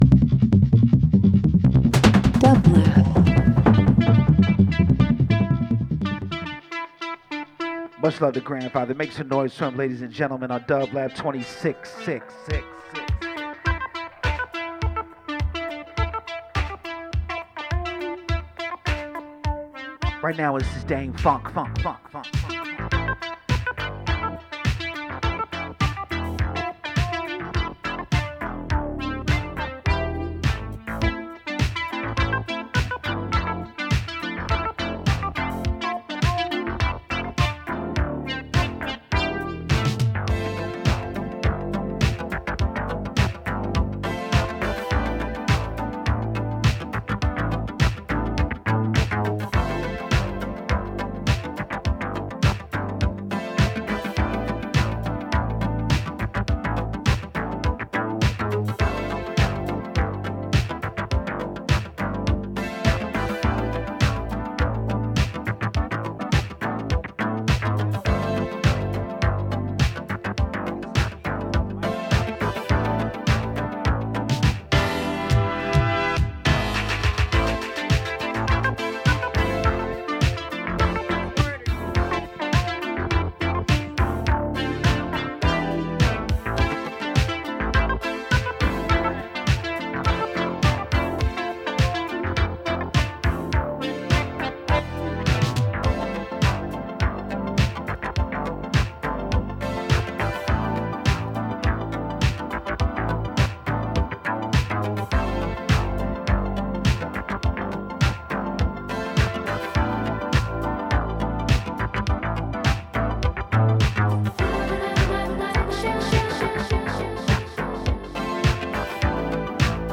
[LIVE FROM THE LOT @ PORTER STREET STUDIO – OCT 11 2025]
Electronic House Live Performance